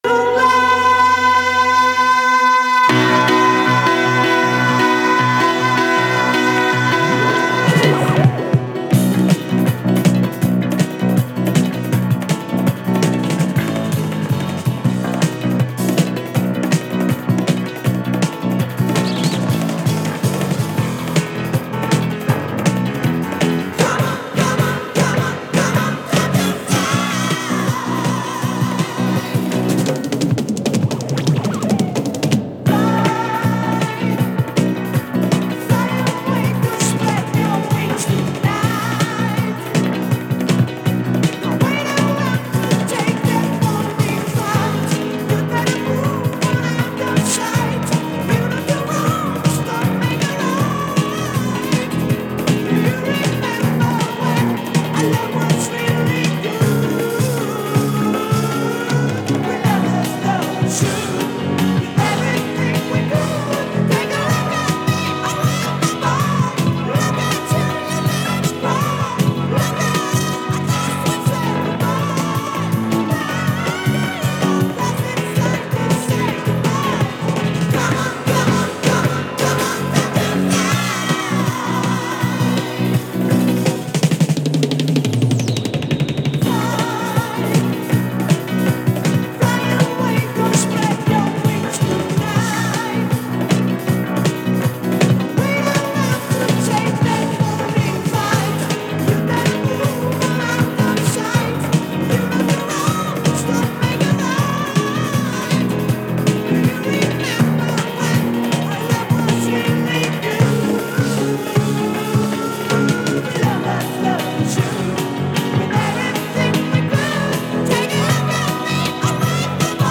フランス南部はトゥールース出身のシンガーによるファースト・アルバム。